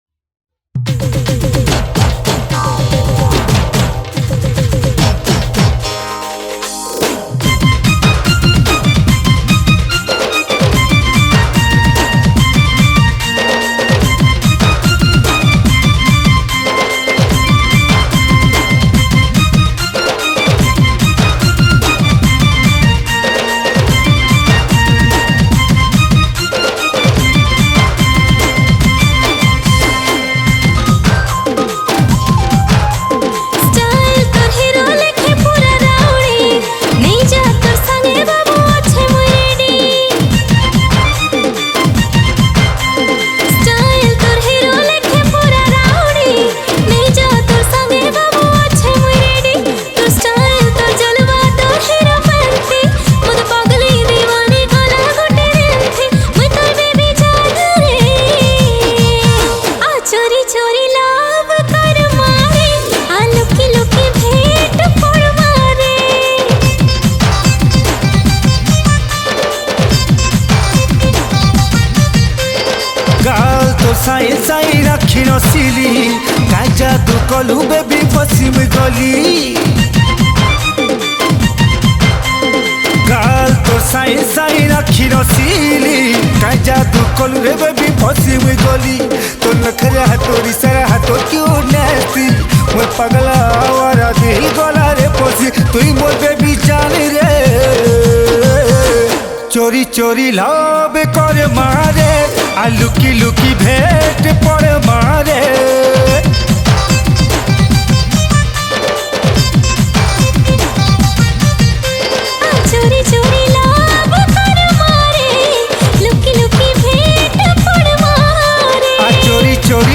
Sambapuri Single Song 2022 Songs Download